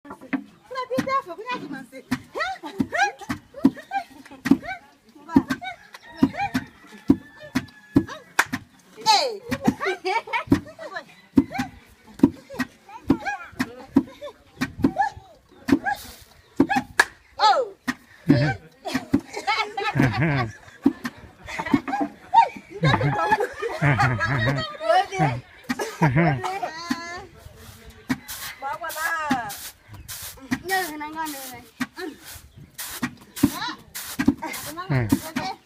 scene-de-vie-dans-un-village-africain-guinee.mp3